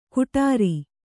♪ kuṭāri